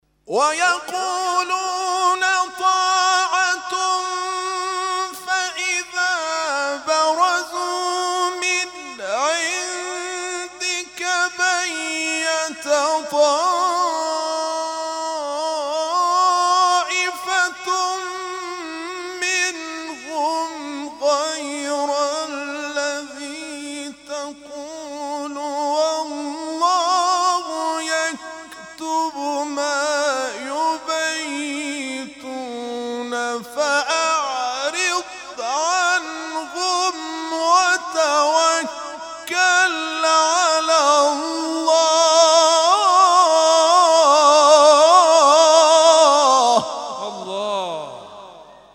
محفل انس با قرآن در آستان عبدالعظیم(ع) + صوت